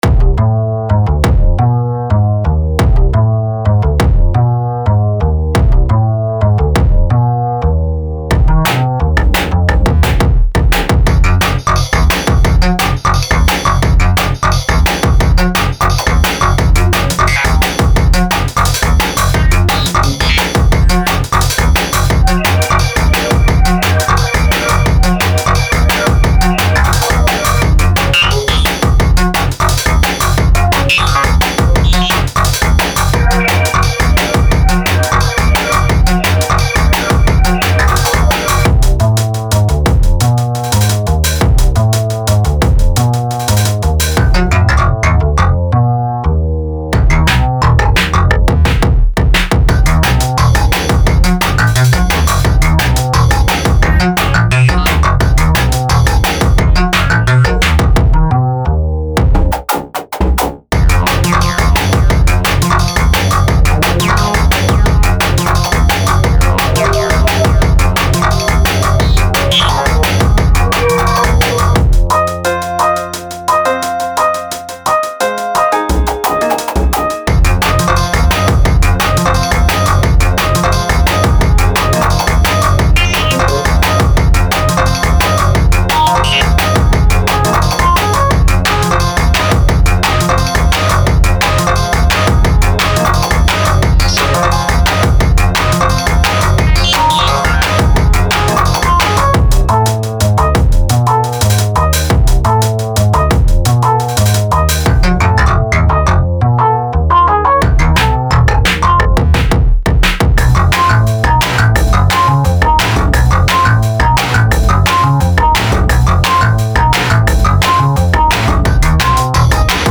Acid Jazz